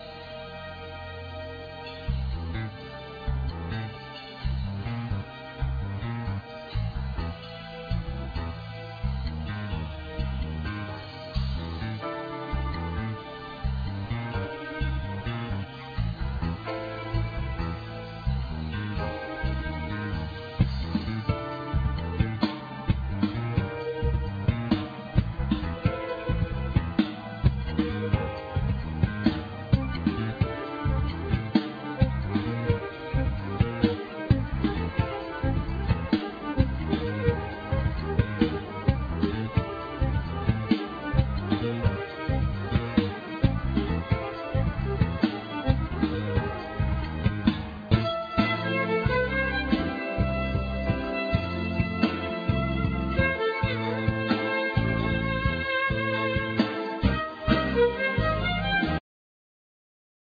Violin
Keyboards
Drums
Bass
El.guitar
Sopranosaxophne